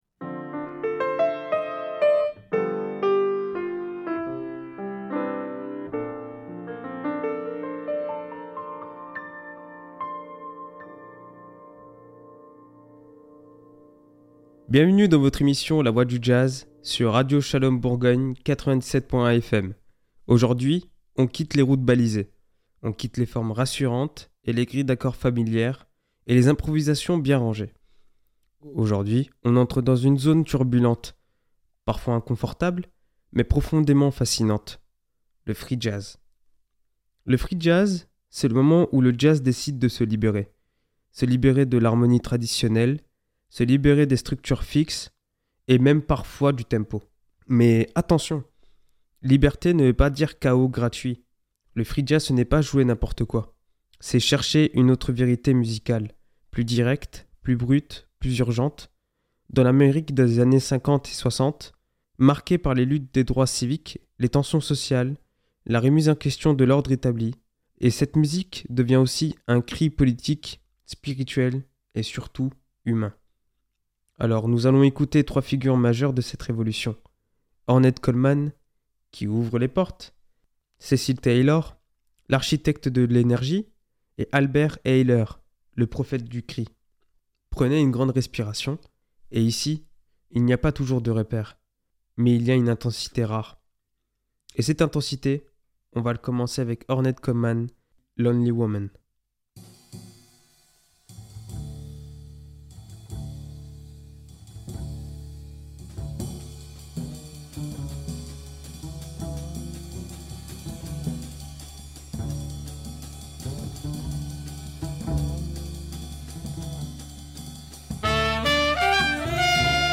Dans cet épisode de La Voie du Jazz, nous plongeons au cœur du Free Jazz, cette musique qui bouscule les règles, libère les formes et explore l’inconnu.